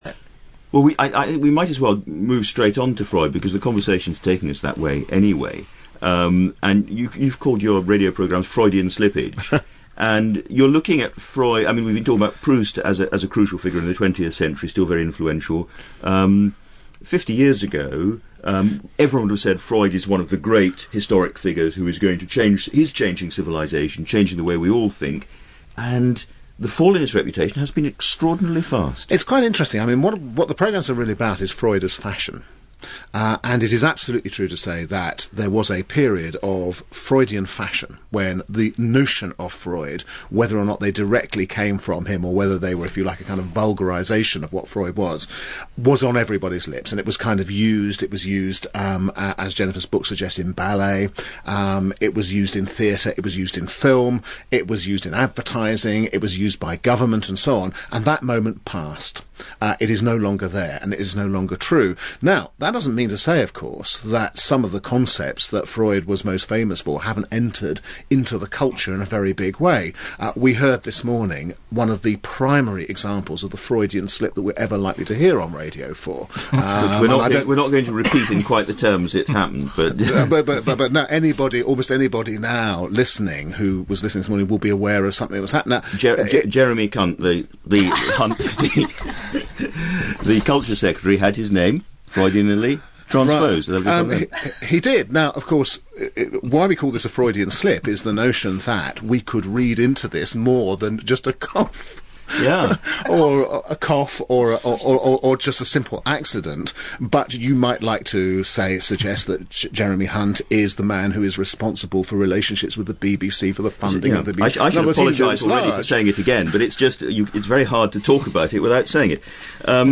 Around 90 minutes later Andrew Marr in the Start the Week programme was hosting a discussion about Naughtie's comment - described by guest David Aaronovitch as a "prime Freudian slip" and said We're not going to repeat in quite the terms it happened " but then went on to do just that, saying "Jeremy Cunt…(slight pause and giggles behind Marr speaking) the Culture Secretary had his name Freudianally transposed He quickly apologised, saying "It's very hard to talk about it without saying it."
Audio of Andrew Marr comment (0.99MB 2mins 10 MP3):